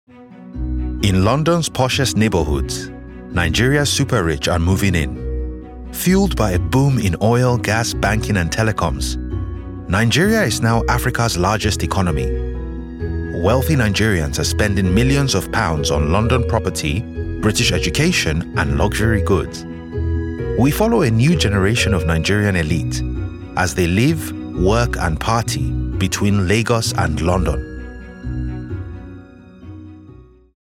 20s-40s. Male. African/Black British.
Narration